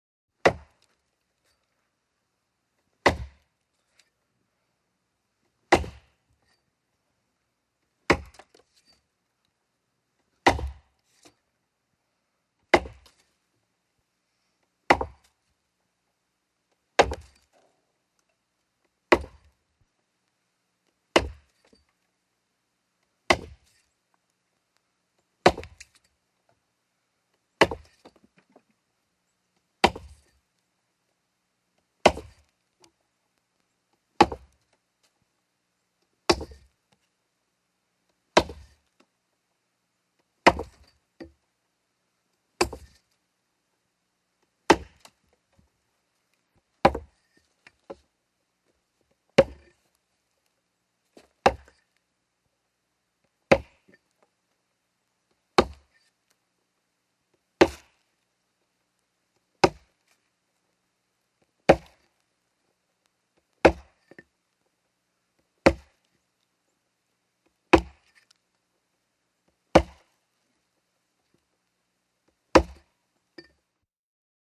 Logging|Exterior
IMPACTS & CRASHES - WOOD LOGGING: EXT: Chopping trees with axe, multiple impacts.